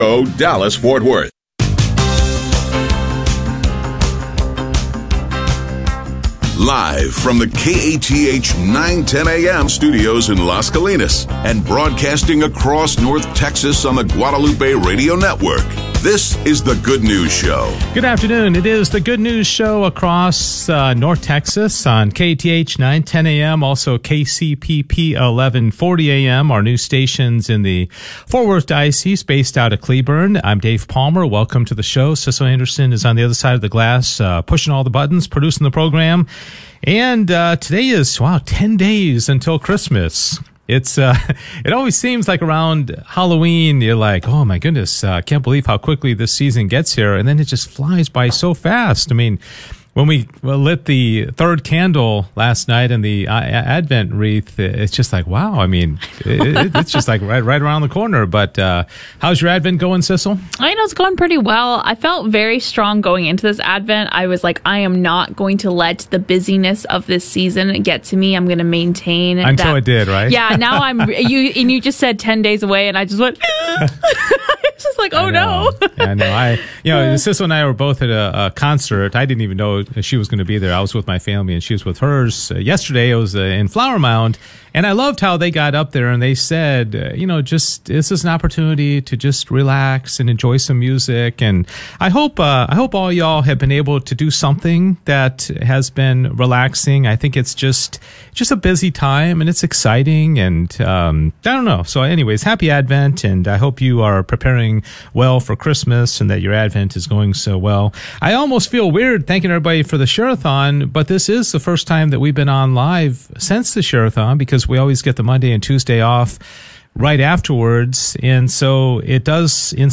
1 Steve Toth: Why Congress Is Broken (And How We Fix It) - SMS #146 1:27:14 Play Pause 3h ago 1:27:14 Play Pause Play later Play later Lists Like Liked 1:27:14 In this episode of Shoot Me Straight, we sit down with Steve Toth, Texas State Representative and current candidate for U.S. Congress, for a wide-ranging, no-nonsense conversation about leadership, character, and the real cost of politics in America.Steve opens up about what pulled him into public service, the realities of navigating the political …